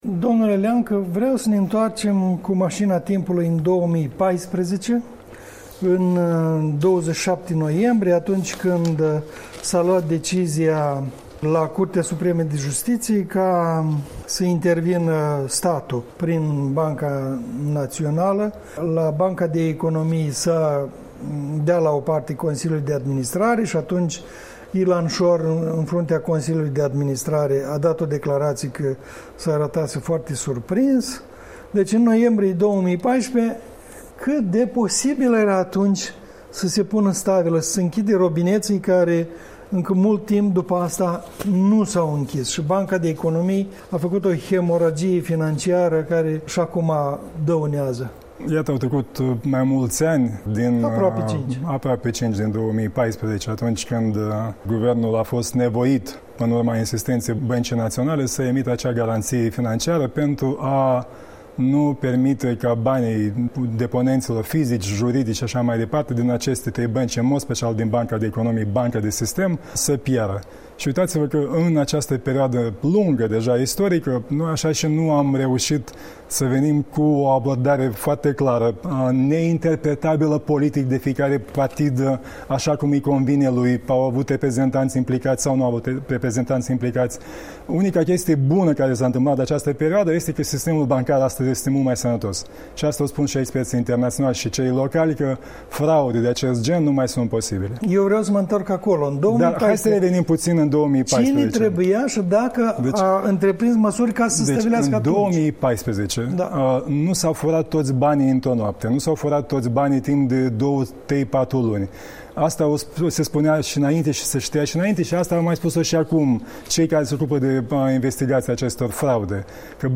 Un interviu cu fostul premier al Republicii Moldova